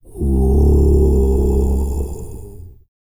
TUVANGROAN10.wav